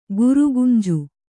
♪ gurugunje